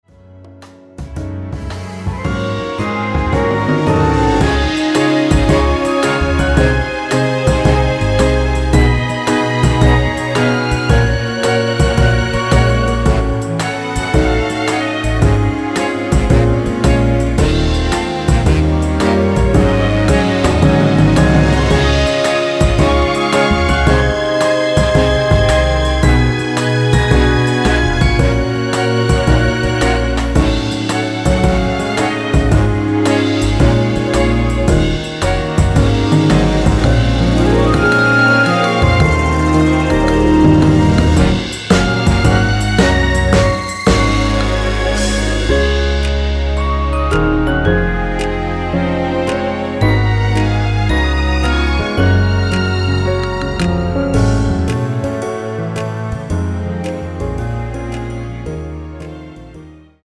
다음 간주가 길어서 4마디로 짧게 편곡 하였습니다.(미리듣기및 본문가사 확인)
원키에서(-1)내린 (1절+후렴)으로 진행되는 MR입니다.
Db
앞부분30초, 뒷부분30초씩 편집해서 올려 드리고 있습니다.